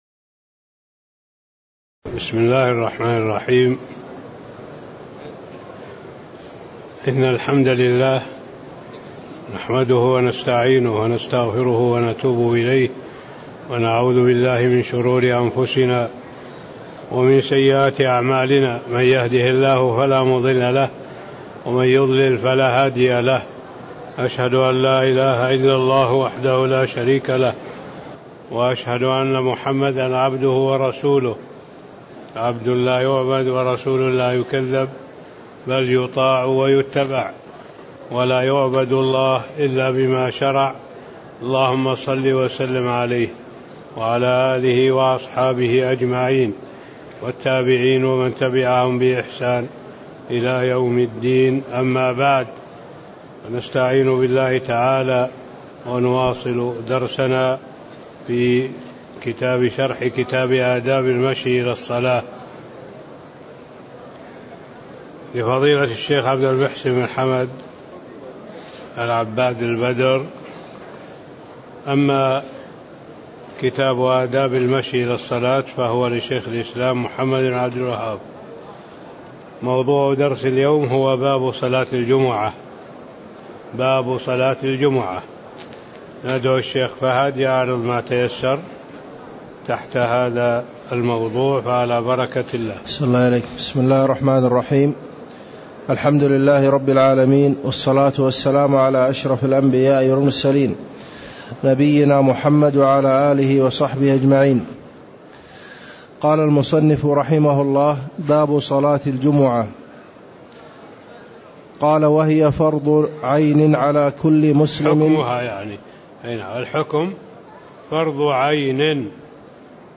تاريخ النشر ٦ ربيع الثاني ١٤٣٦ هـ المكان: المسجد النبوي الشيخ